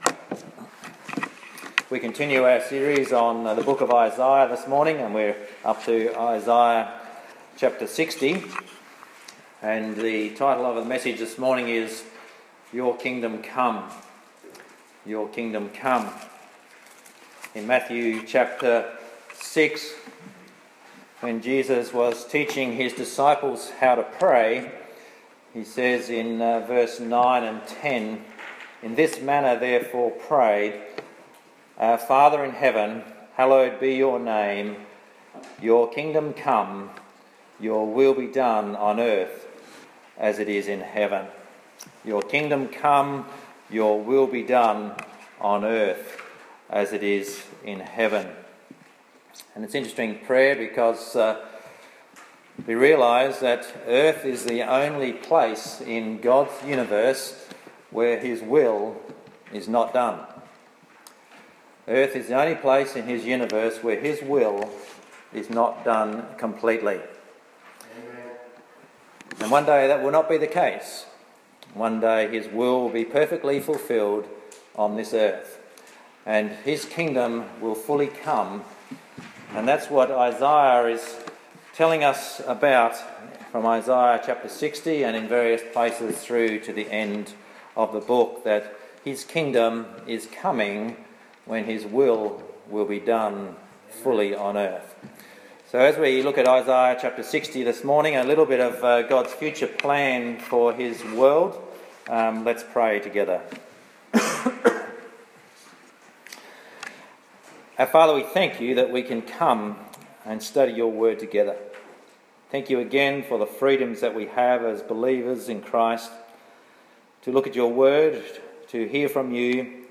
8.5.18-Sunday-Service-Your-Kingdom-Come.mp3